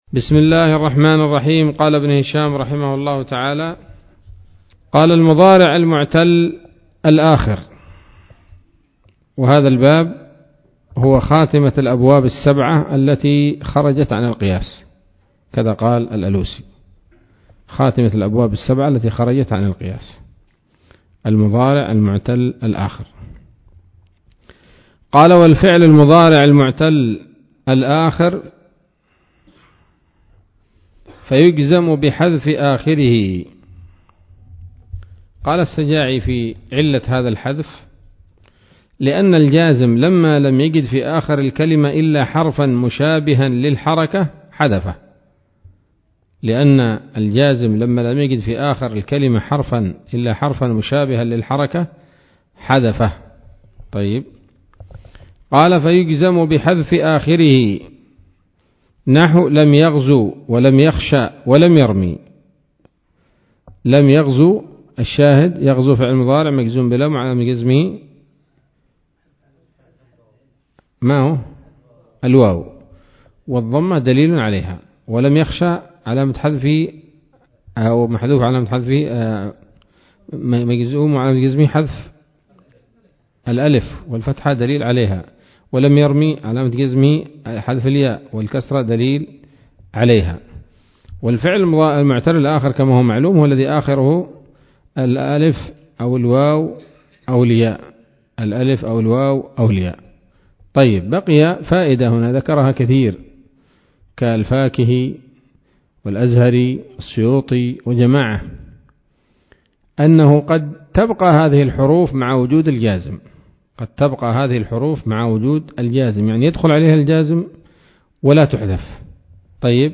الدرس الثالث والعشرون من شرح قطر الندى وبل الصدى [1444هـ]